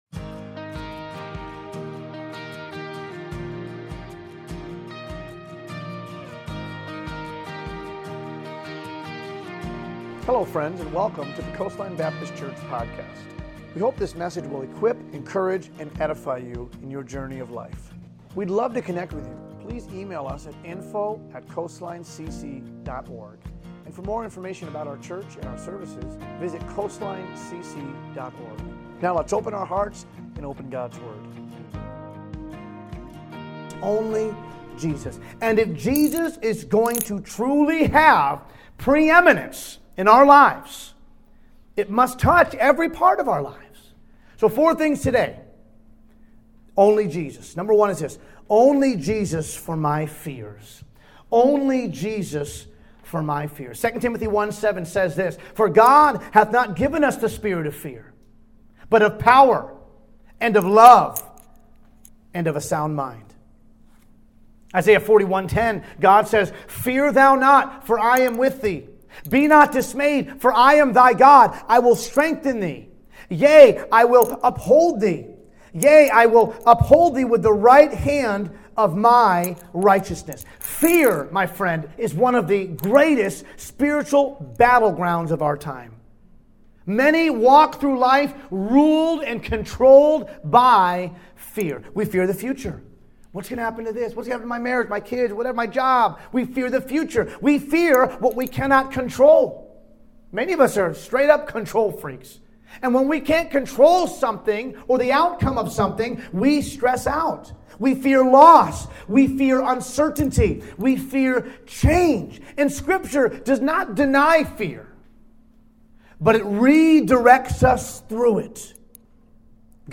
Only Jesus sermon.mp3